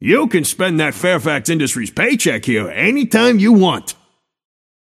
Shopkeeper voice line - You can spend that Fairfax Industries paycheck here anytime you want.
Shopkeeper_hotdog_t4_mcginnis_04.mp3